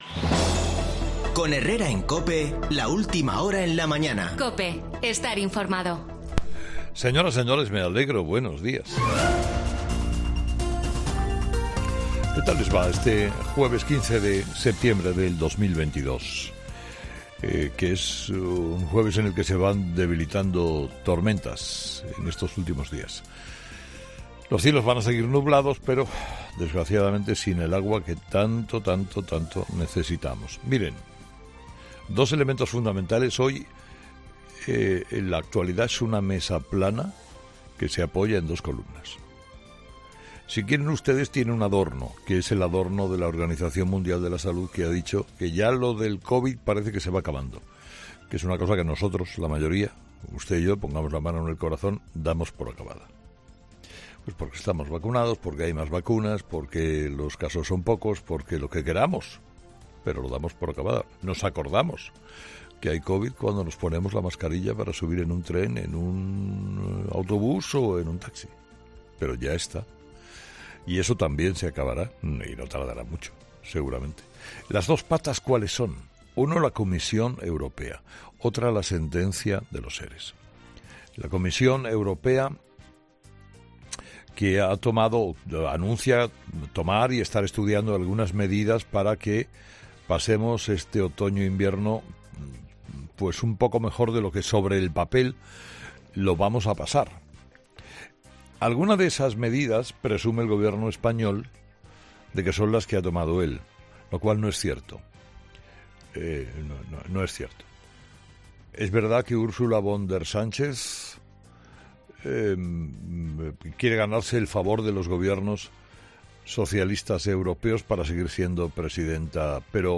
Los cielos van a seguir nublados pero desgraciadamente sin el agua que tanto tanto, tanto necesitamos. 00:00 Volumen Descargar Escucha el monólogo de Carlos Herrera de este jueves 15 de septiembre - Miren, dos elementos fundamentales hoy.